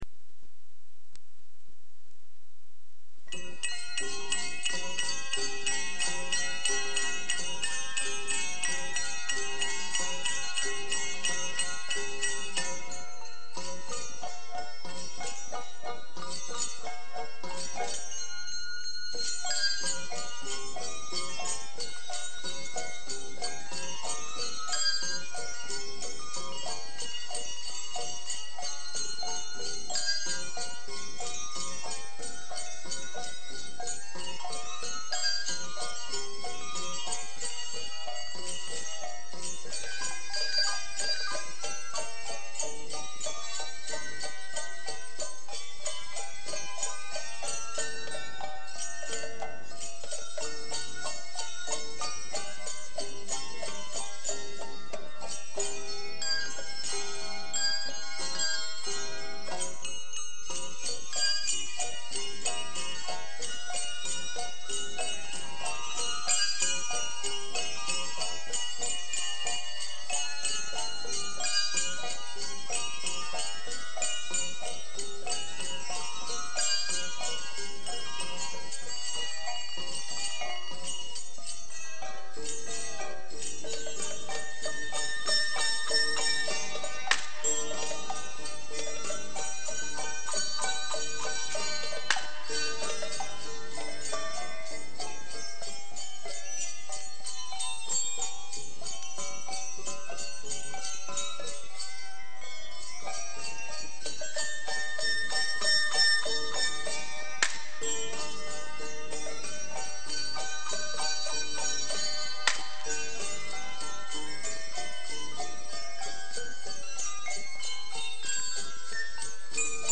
Collection: Featuring Bells
Genre: Traditional Instrumental | Type: Christmas Show |